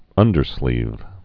(ŭndər-slēv)